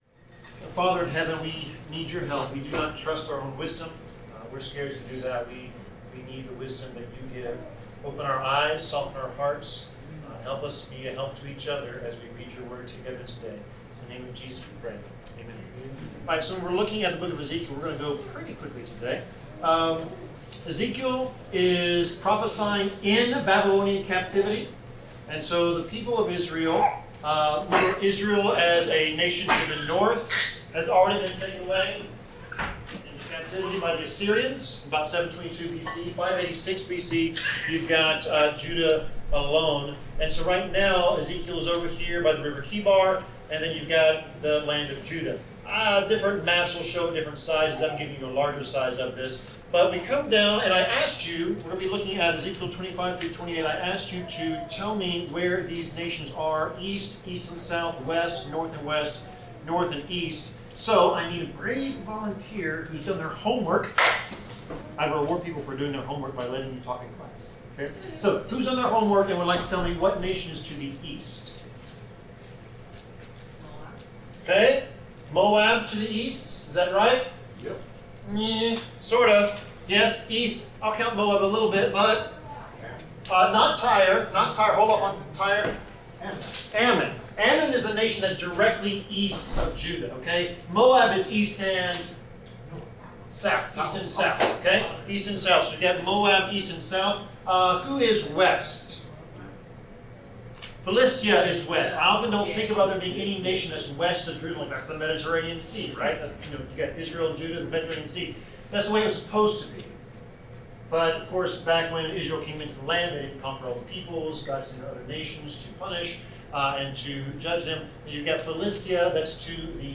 Bible class: Ezekiel 25-28
Passage: Ezekiel 25-28 Service Type: Bible Class